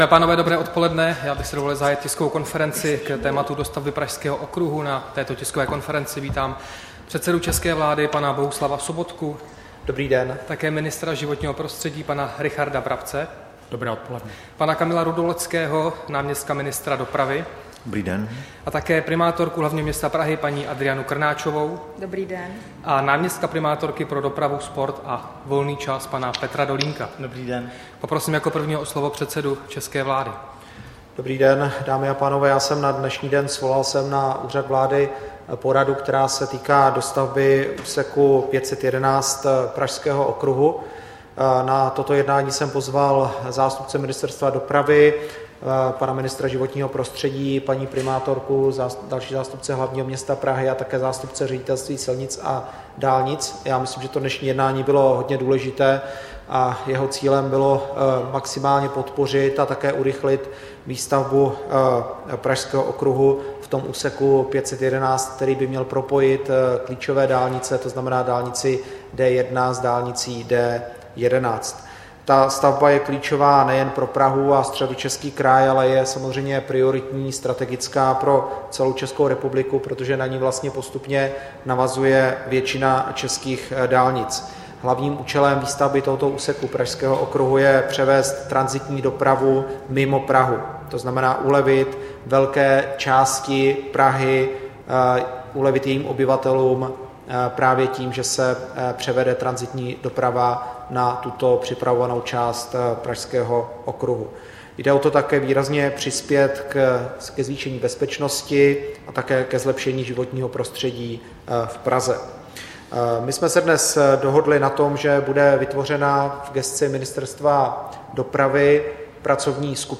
Tisková konference po setkání se zástupci pražského magistrátu a ŘSD k tématu dostavby Pražského okruhu 7. září 2016